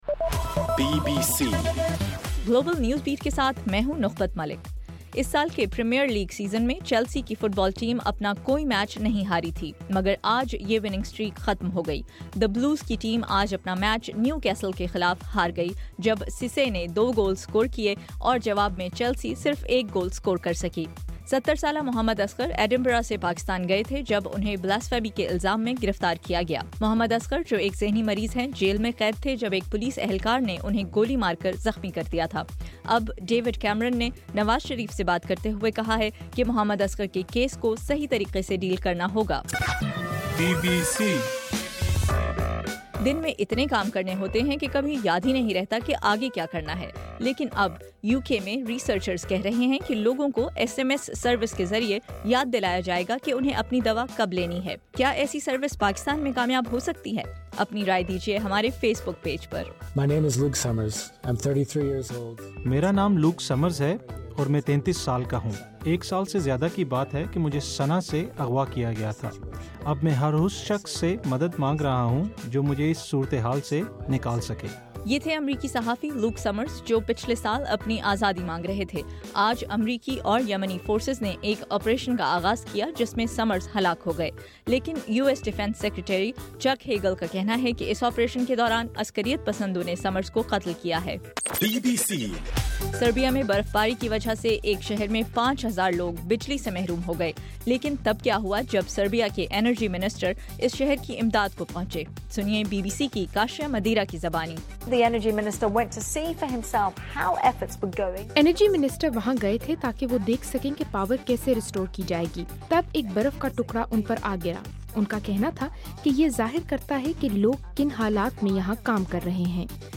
دسمبر 6: رات 11 بجے کا گلوبل نیوز بیٹ بُلیٹن